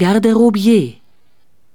Aussprache:
🔉[ɡardəroˈbi̯eː]